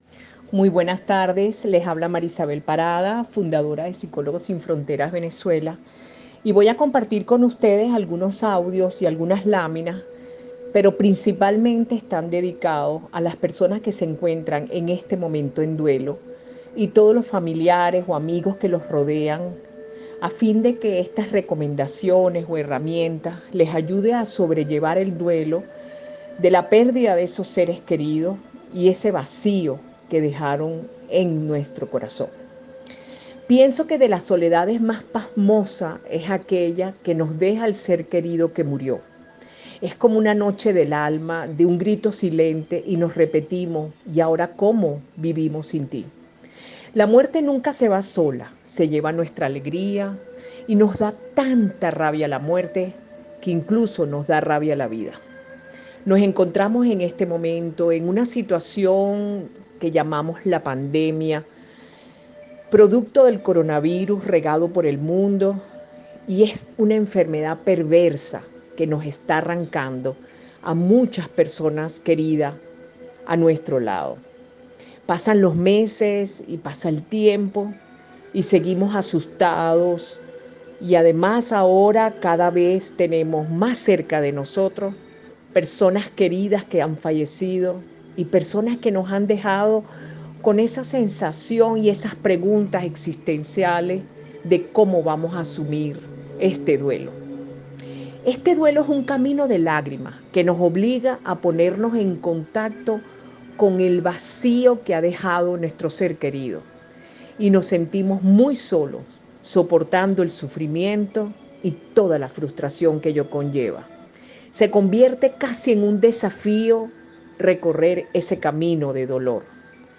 A continuación les presentamos los audios con su ponencia.